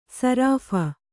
♪ sarāpha